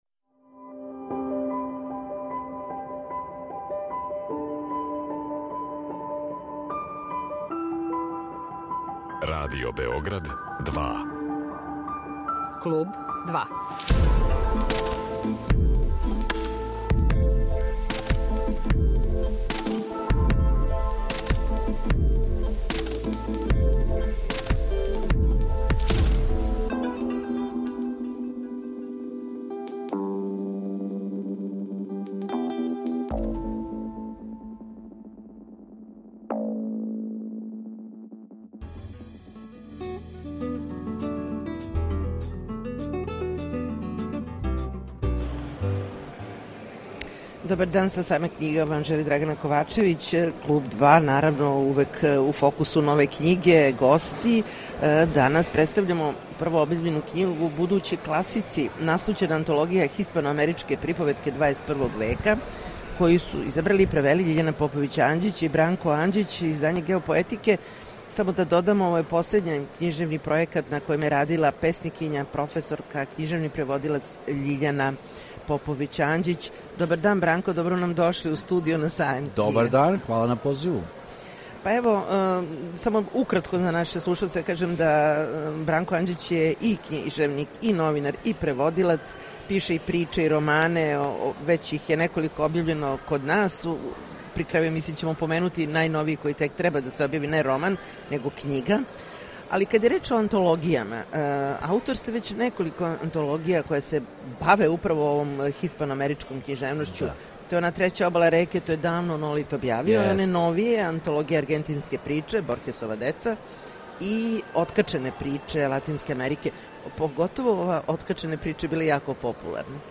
На Сајму књига